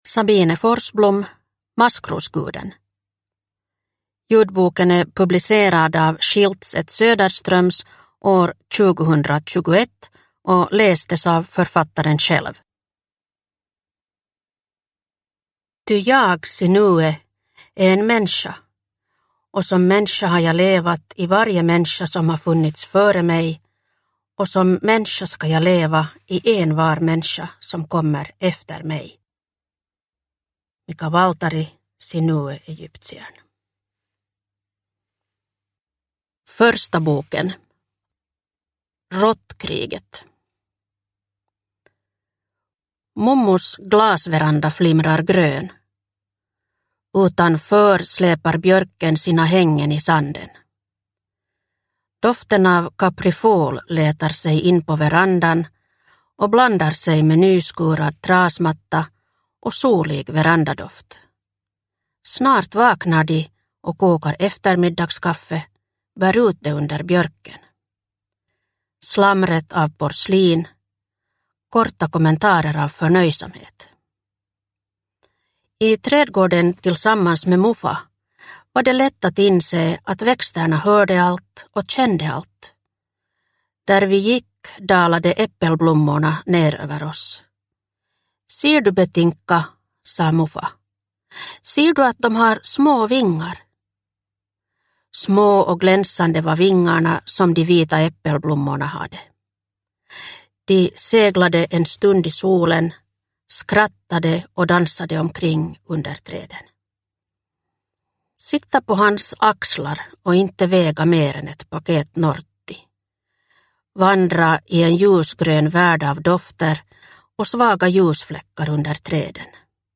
Maskrosguden – Ljudbok – Laddas ner